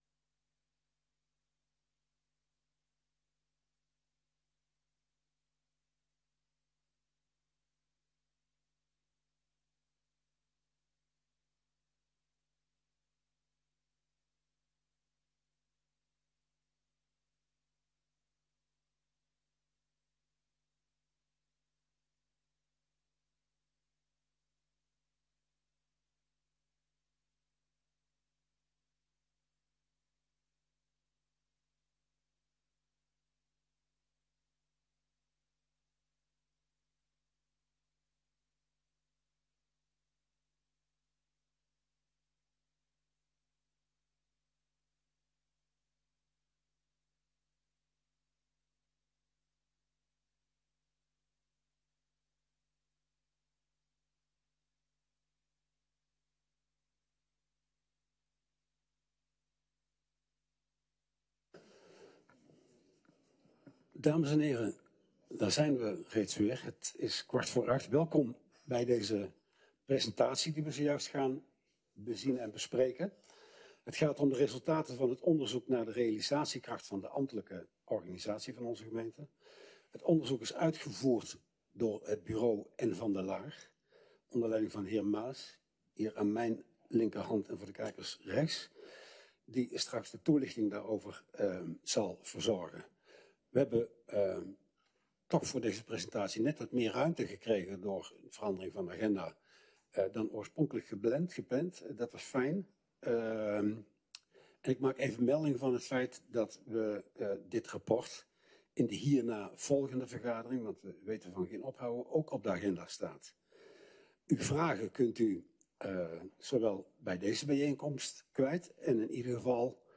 Presentatie Realisatiekrachtonderzoek
Locatie: Raadzaal